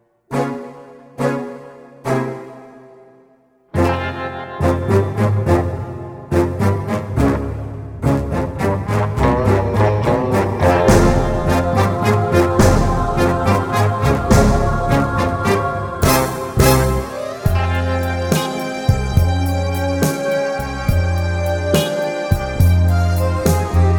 One Semitone Down Jazz / Swing 4:13 Buy £1.50